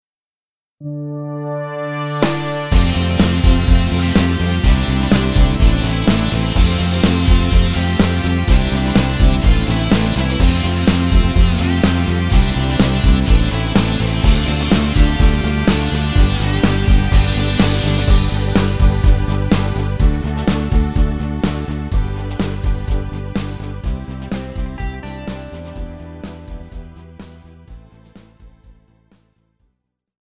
Žánr: Pop
Key: G
MP3 ukázka